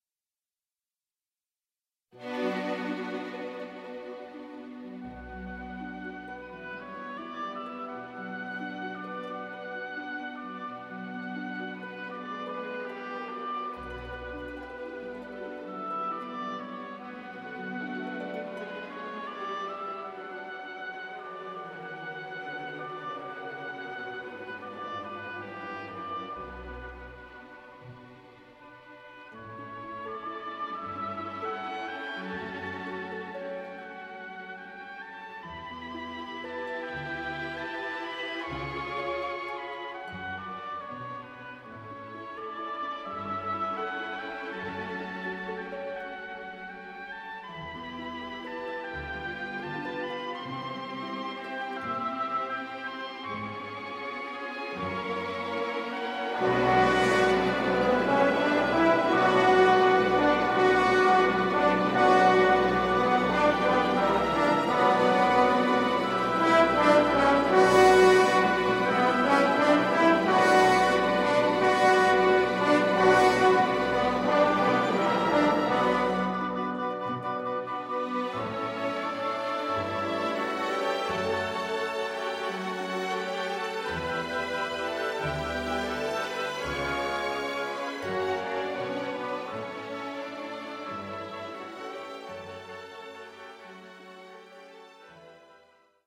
Schlagworte Ballett • Ballettmusik • Filmmusik & Bühnenmusik • Filmmusik & Bühnenmusik • Kinder, Jugendliche und Bildung • Klassik-CDs Kinder-CDs • Klassik für Kinder • Schwanensee